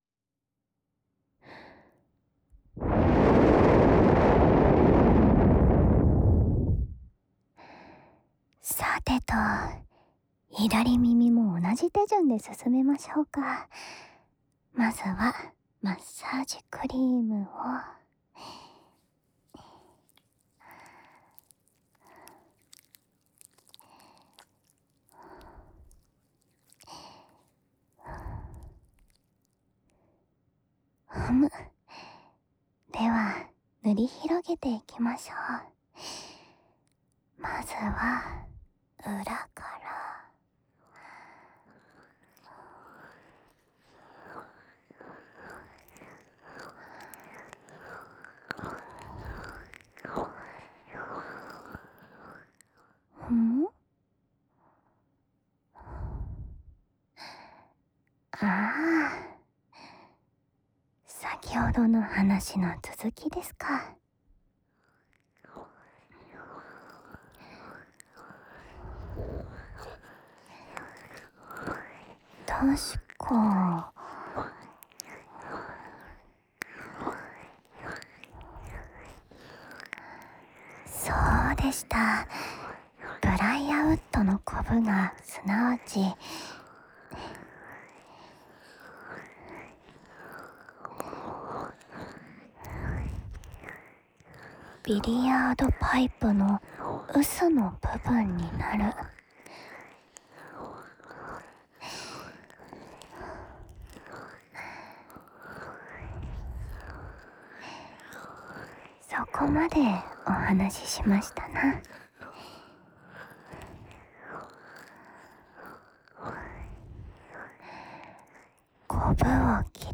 05_名侦探的象征・和烟斗一样的用石楠木造出的高级掏耳勺，陆六六的掏耳.m4a